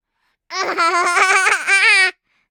tickle4.ogg